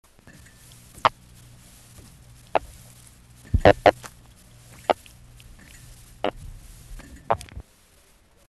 Sounds Made by Larimus breviceps
Sound produced yes, active sound production
Type of sound produced escape sounds, thumps followed by "hornlike" burst
Sound production organ swim bladder with associated muscles & pharyngeal teeth
Behavioural context under duress (manual & electric stimulation)